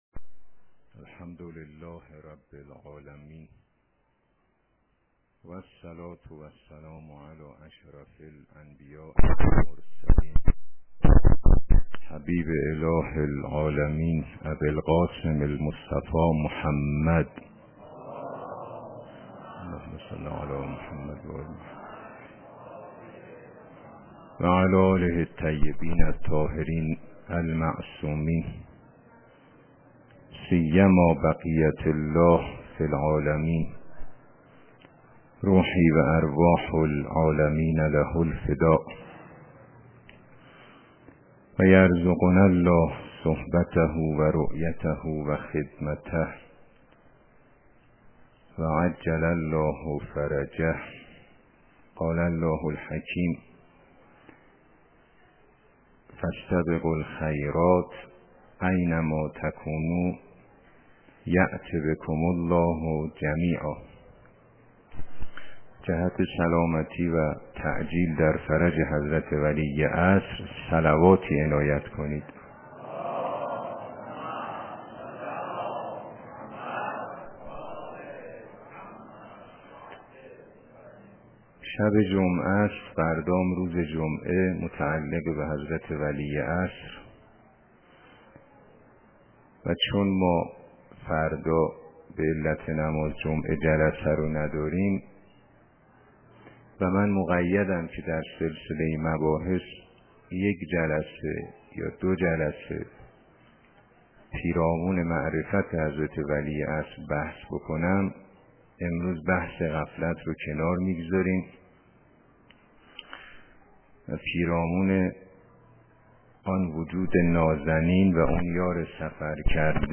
برنامه سخنرانی وی، هر روزه پس از نماز ظهر و عصر در مسجدالنبی(ص) خواهد بود.